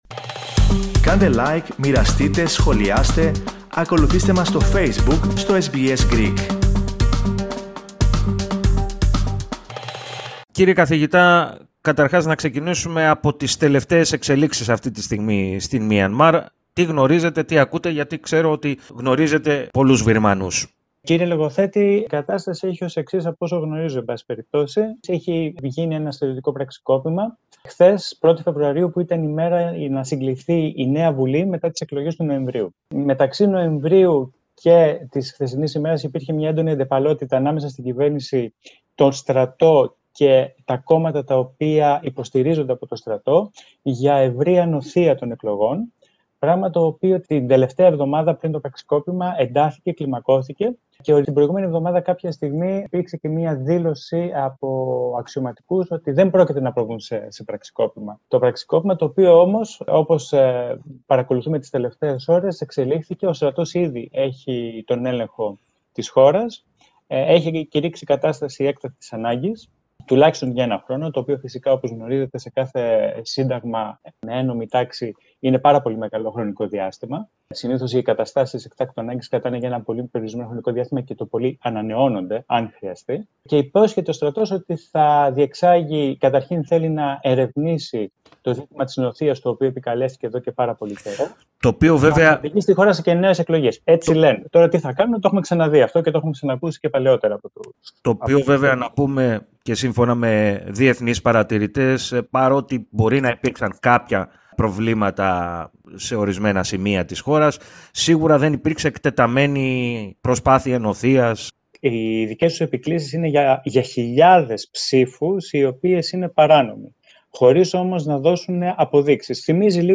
Την κατάσταση που οδήγησε στο πραξικόπημα, ανέλυσε στο Ελληνικό Πρόγραμμα, της ραδιοφωνίας SBS